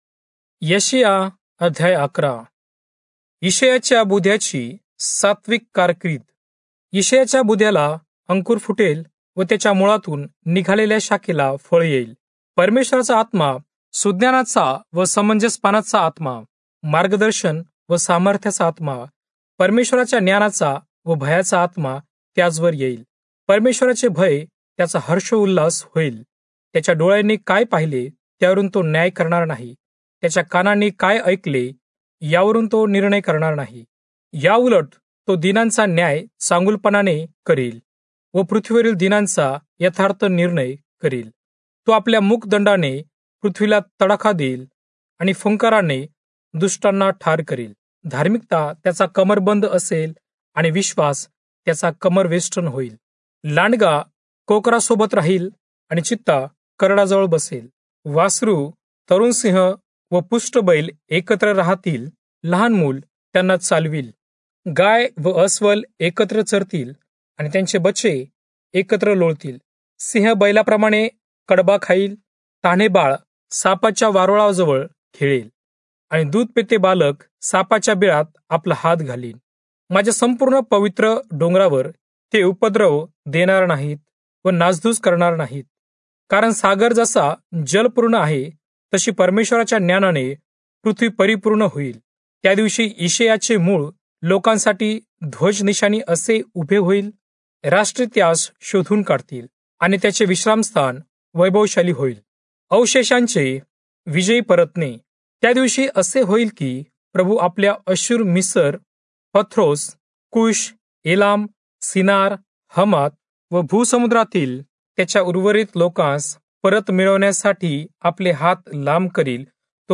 Marathi Audio Bible - Isaiah 6 in Irvmr bible version